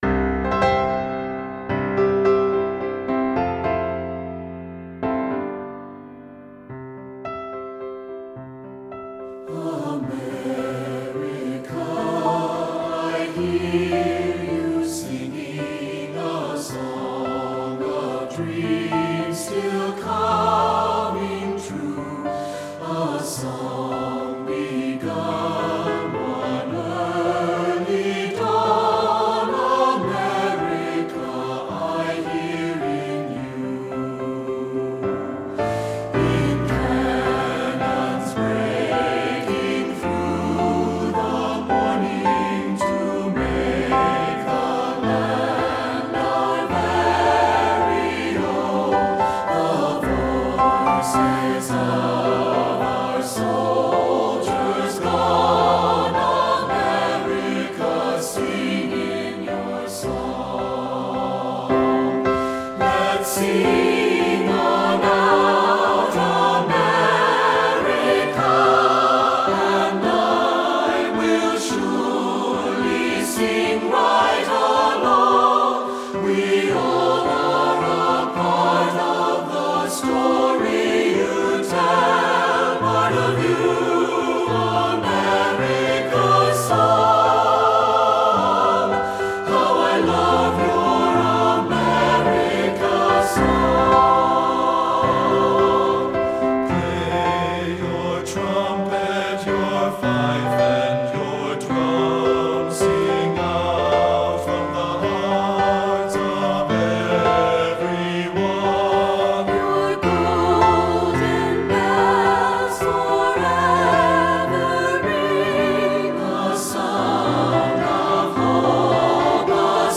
• Soprano
• Alto
• Tenor
• Bass
• Keyboard
Studio Recording
fresh patriotic anthem
Ensemble: Mixed Chorus
Accompanied: Accompanied Chorus